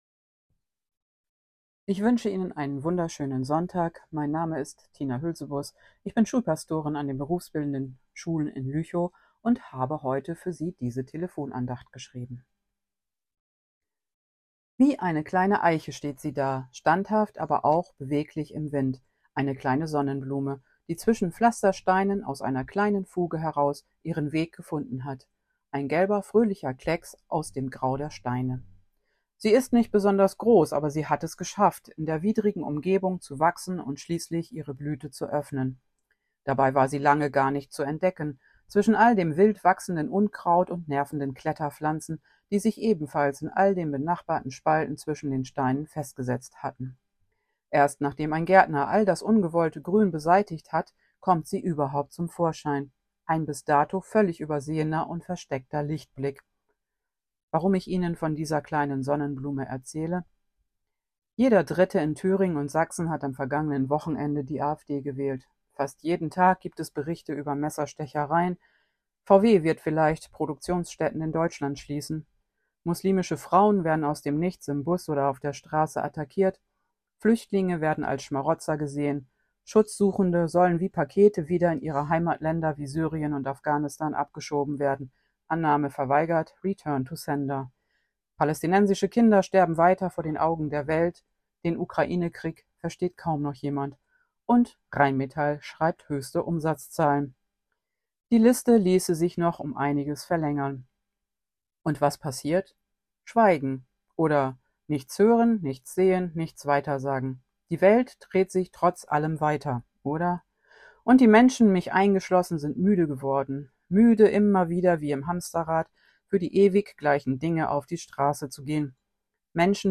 Gib nicht auf ~ Telefon-Andachten des ev.-luth. Kirchenkreises Lüchow-Dannenberg Podcast